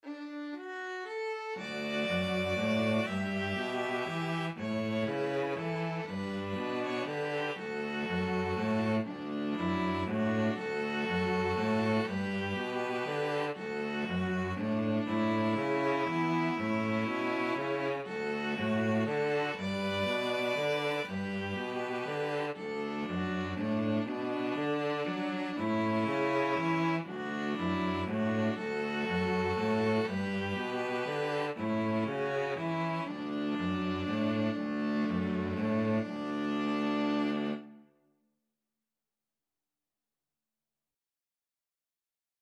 ViolinViolaCello
3/4 (View more 3/4 Music)
D major (Sounding Pitch) (View more D major Music for String trio )
= 120 Slow one in a bar
String trio  (View more Easy String trio Music)
Traditional (View more Traditional String trio Music)